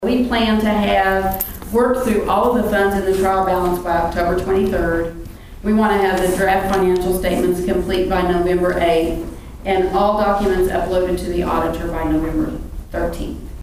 At Tuesday evening's city council meeting in Pawhuska, City Manager Carol Jones gave an update on how the 2024 fiscal year audit is coming along.